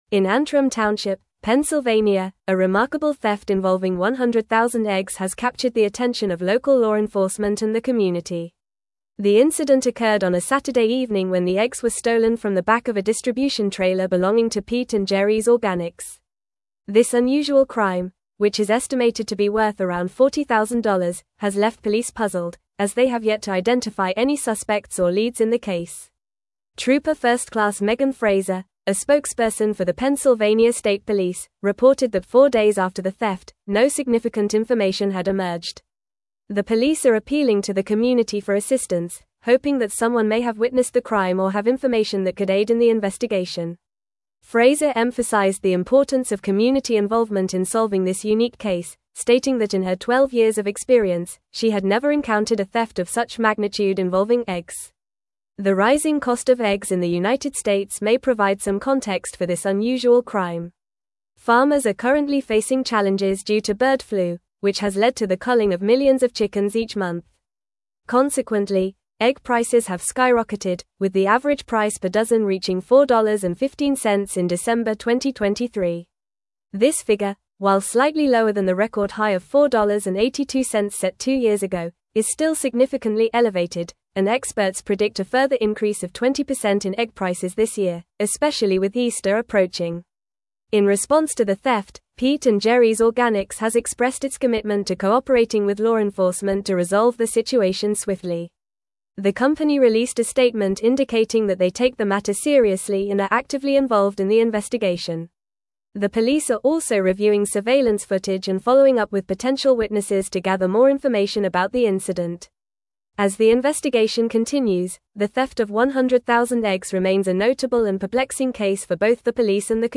Fast
English-Newsroom-Advanced-FAST-Reading-Massive-Egg-Theft-Leaves-Pennsylvania-Authorities-Searching-for-Clues.mp3